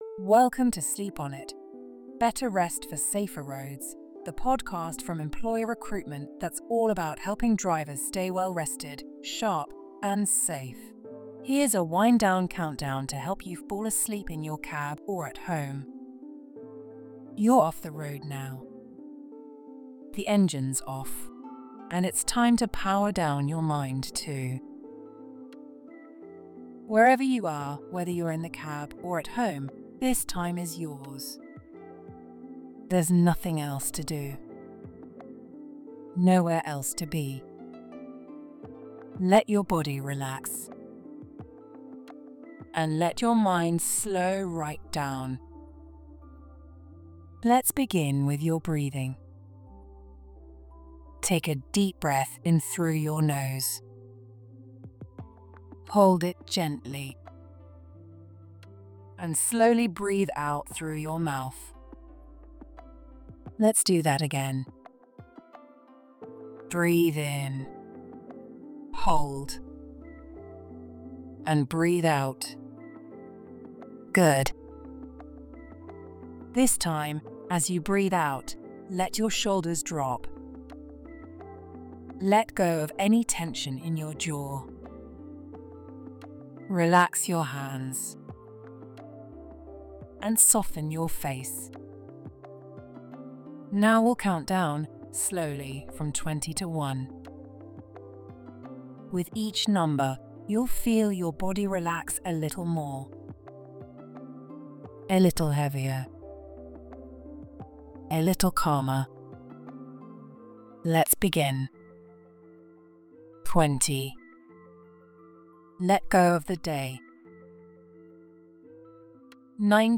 Wind Down Countdown: Guided Sleep Audio
Struggling to switch off? This relaxing countdown helps calm your body and mind, whether you’re in the cab or at home